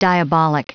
Prononciation du mot : diabolic